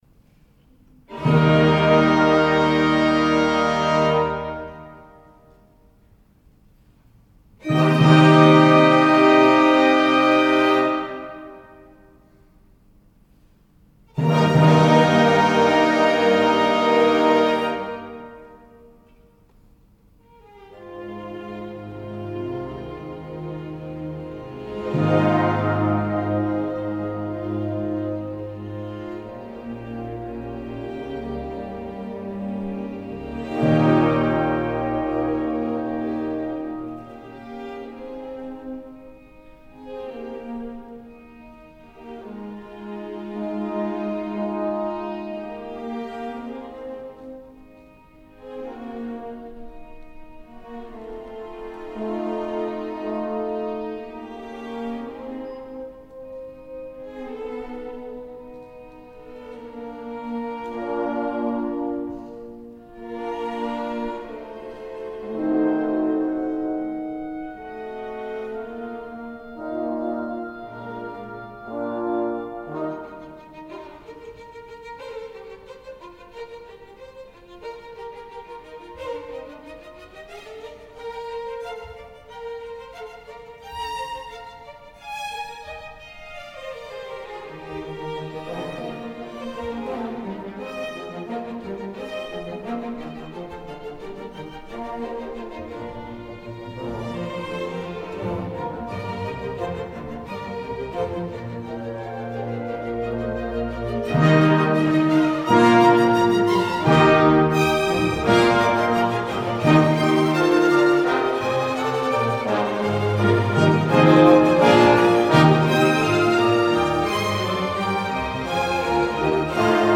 Overture from The Magic Flute (Live recording
Conductor: Trisdee na Patalung Orchestra: Siam Philharmonic Orchestra Location Thailand Cultural Centre , Bangkok